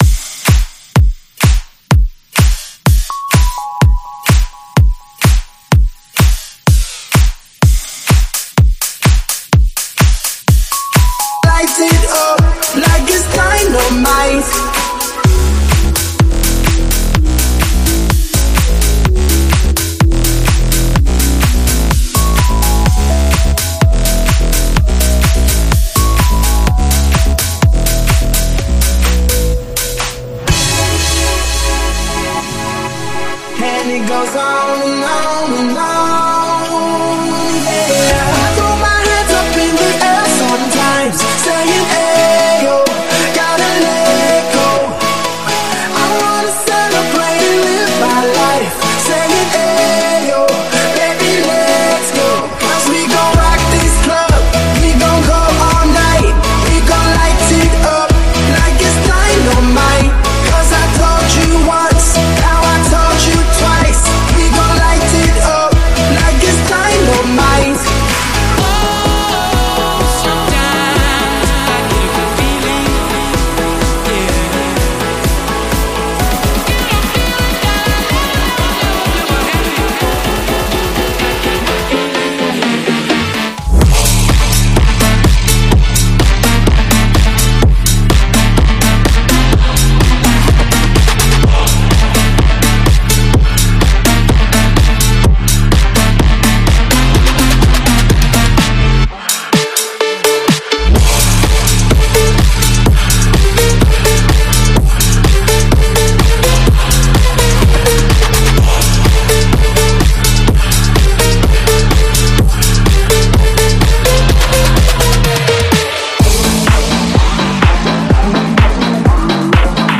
试听文件为低音质